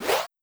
Whoosh & Slash
Whoosh2.wav